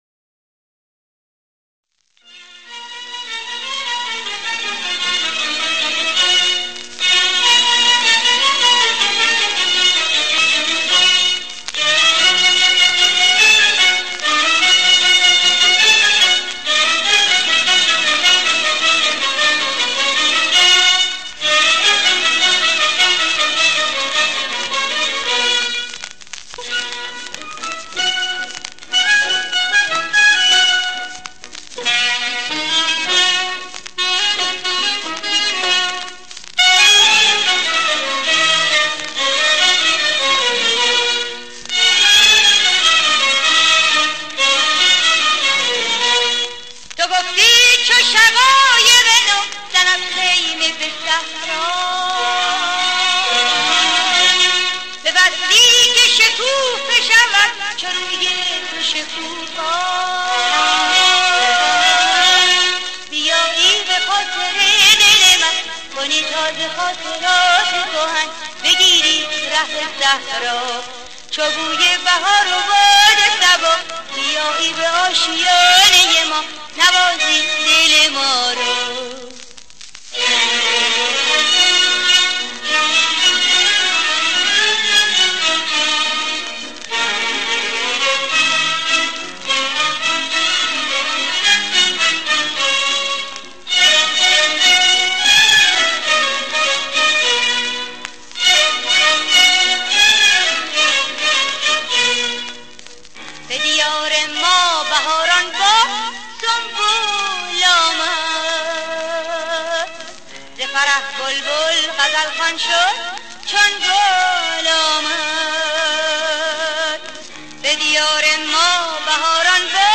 دستگاه: ماهور